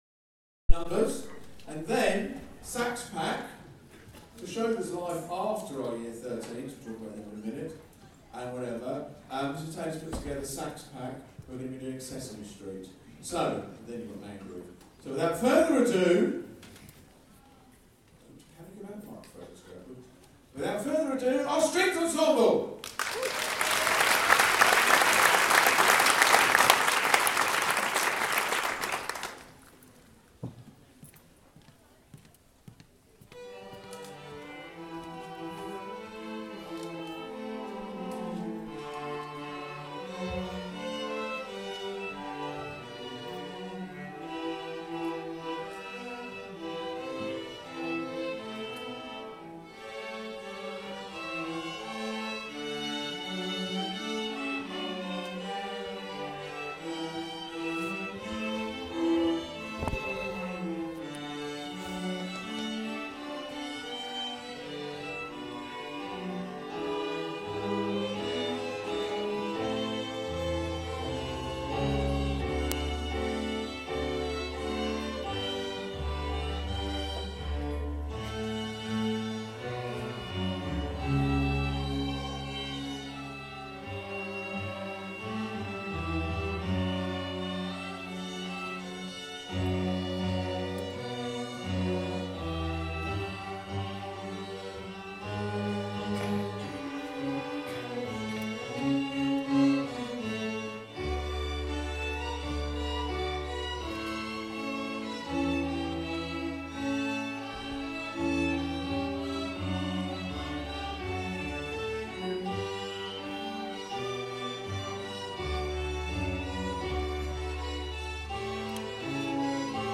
strings